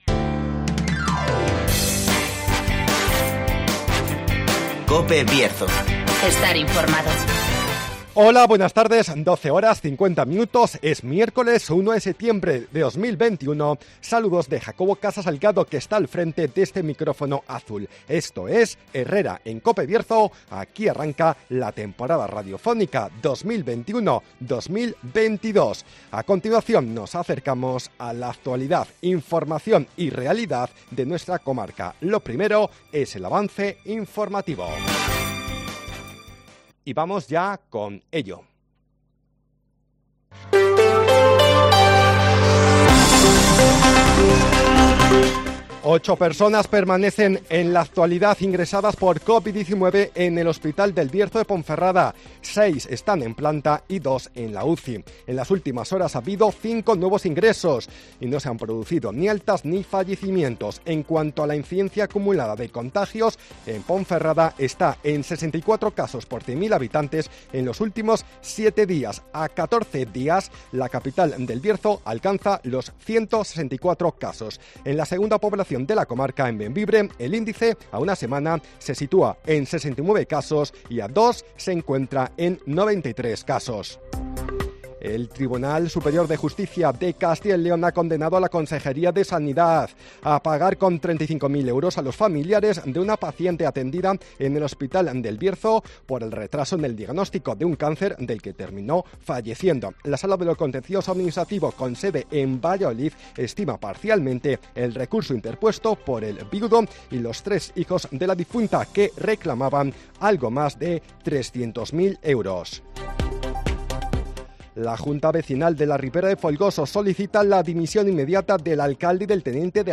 Avance informativo, El Tiempo, Agenda y apuntes del deporte